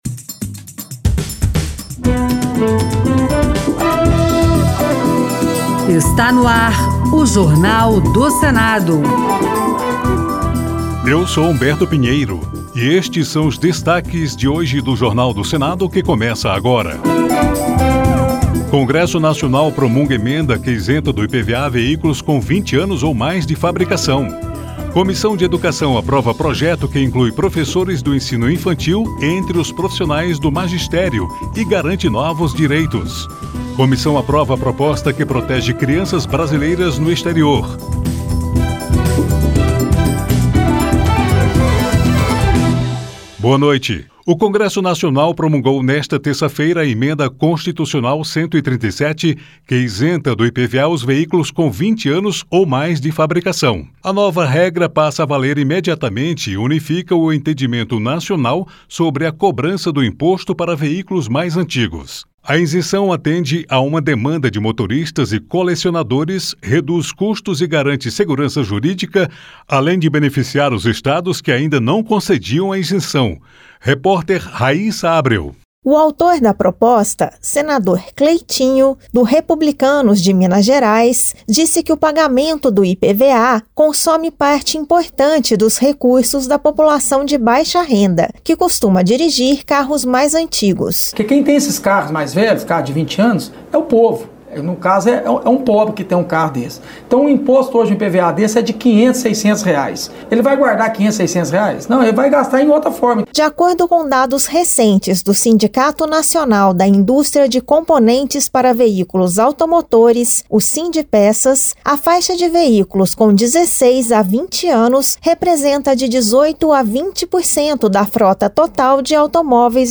Jornal do Senado — Rádio Senado